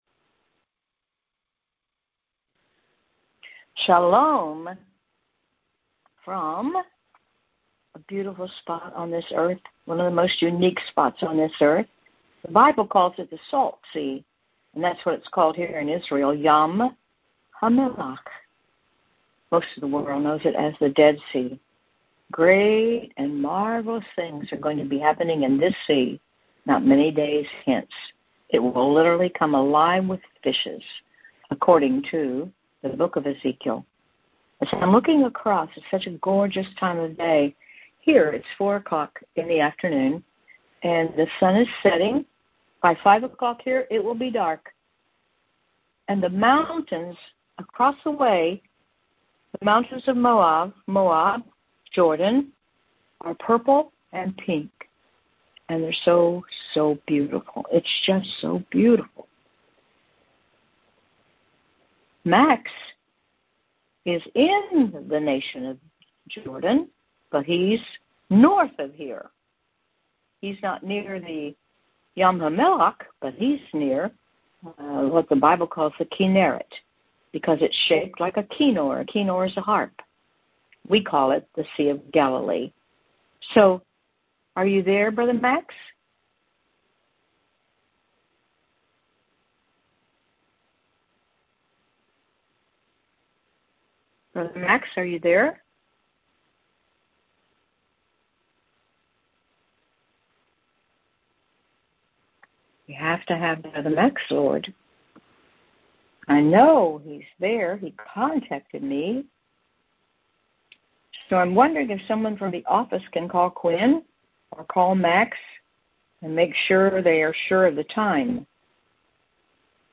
Prayer Call